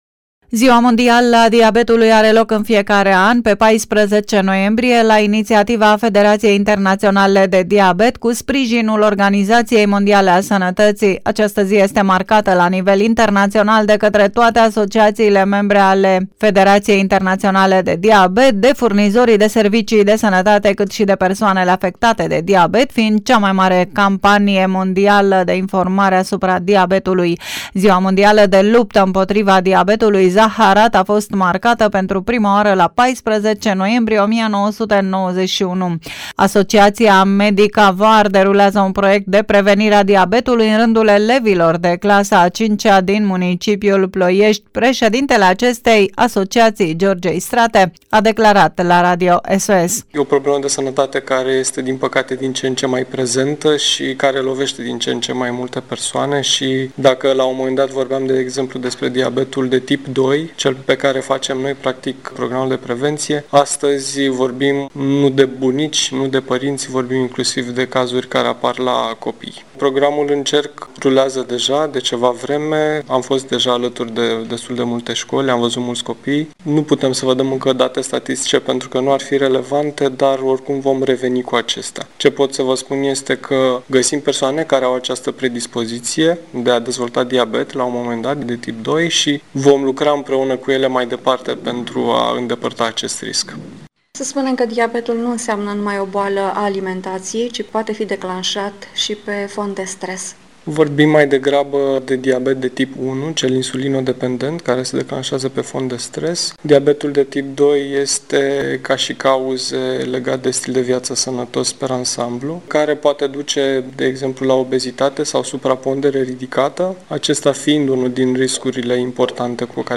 Reportaje și interviuri radio difuzate la Radio SOS Prahova, în data de 14 noiembrie 2017, cu ocazia Zilei Mondiale a Diabetului.
Știre Radio SOS Ploiești Ziua Mondială a Diabetului
14-Noiembrie-Stire-Radio-SOS-Ploiesti-Ziua-Mondiala-a-Diabetului.mp3